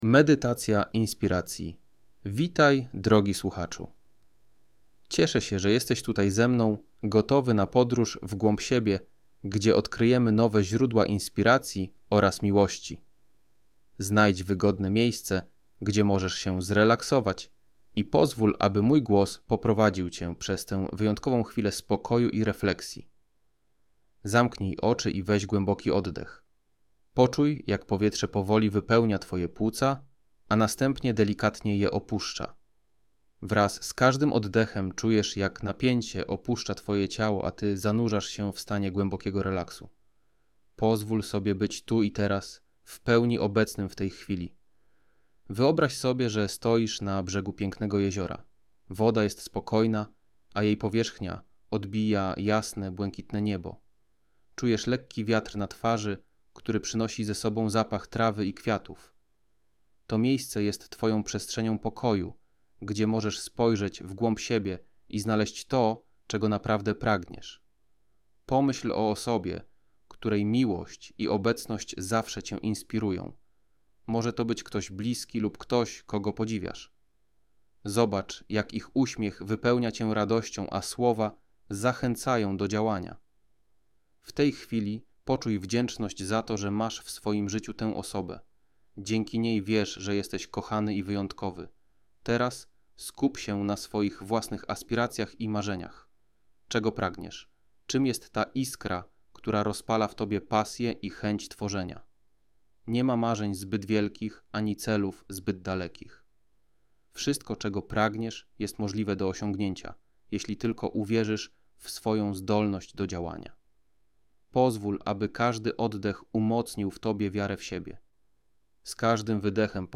• Audiobook MP3 z prowadzoną medytacją,